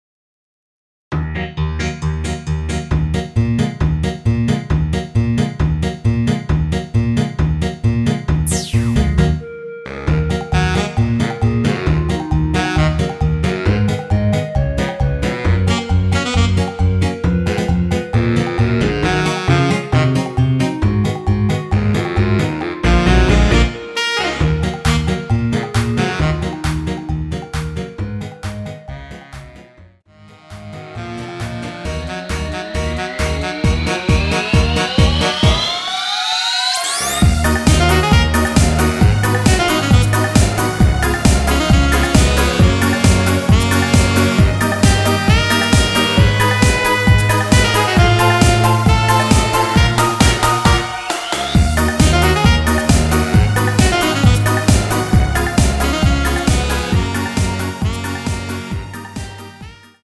(-3)내린 멜로디 포함된 MR이며 멜로디 음색을 앞부분과 뒷부분을 다르게 제작하였습니다.(미리듣기 참조)
Ebm
앞부분30초, 뒷부분30초씩 편집해서 올려 드리고 있습니다.
중간에 음이 끈어지고 다시 나오는 이유는